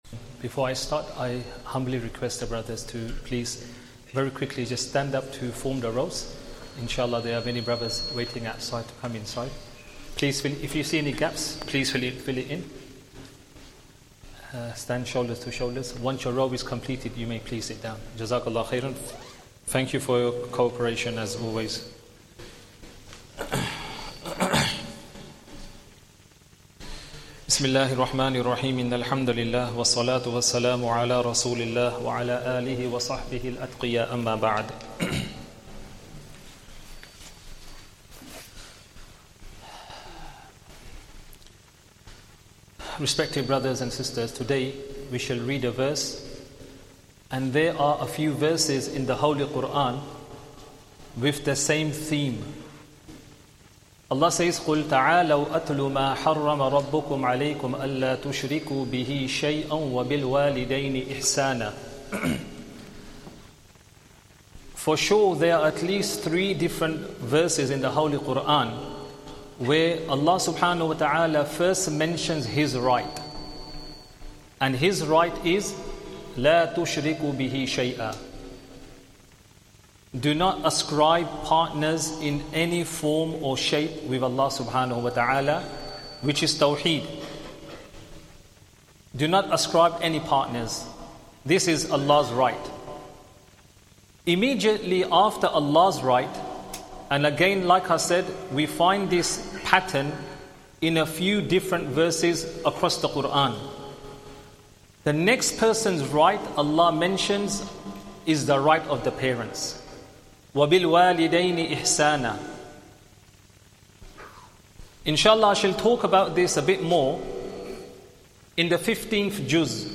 Masjid Adam | Esha Talk & Jammat | eMasjid Live
Esha Talk & Jammat